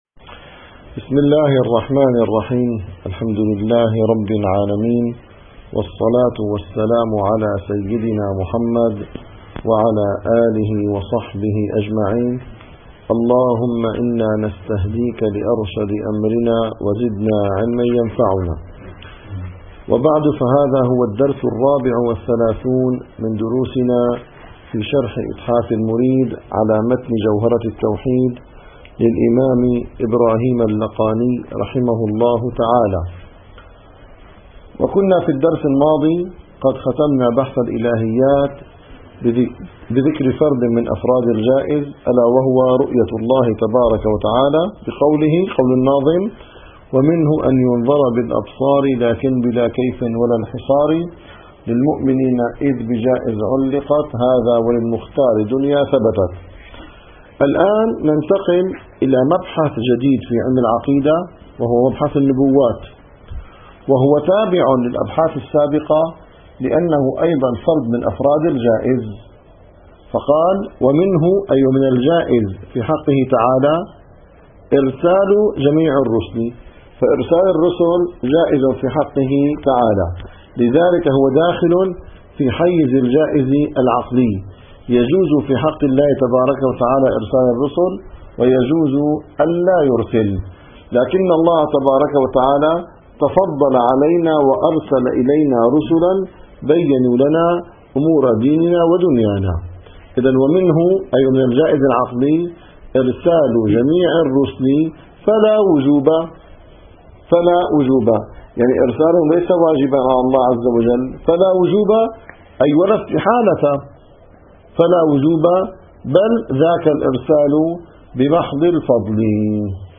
- الدروس العلمية - إتحاف المريد في شرح جوهرة التوحيد - 34- مبحث النبوات جواز إرسال الرسل